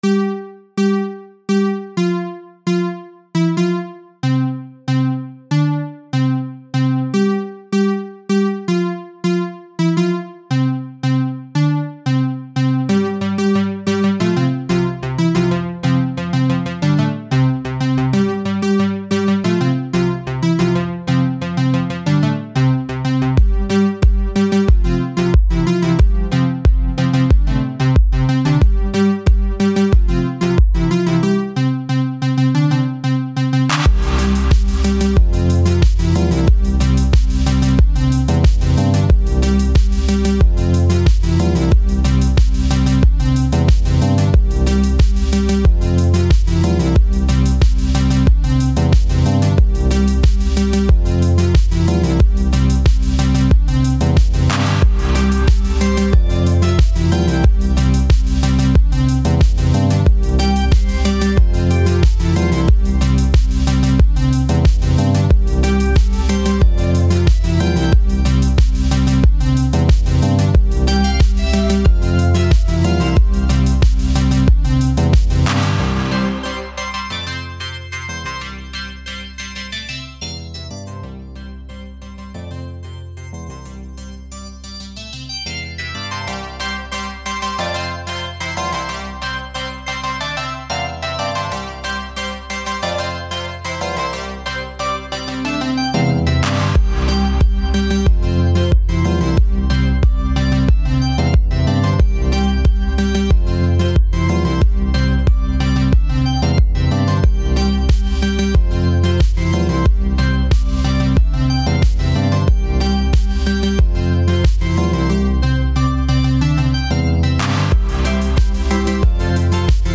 Game Music
electro
upbeat